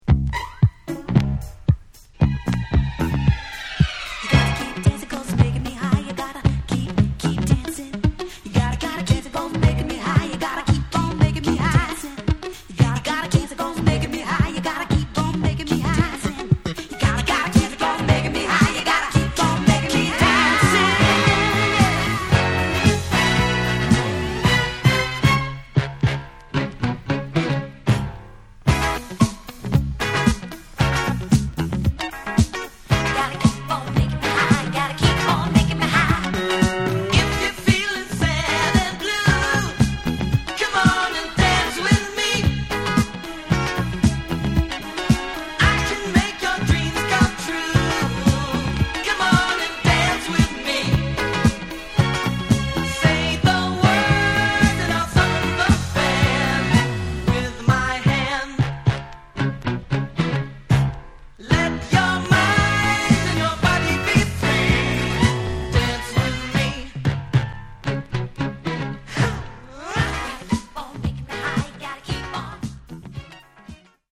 This copy's A-side audio is pristine Mint.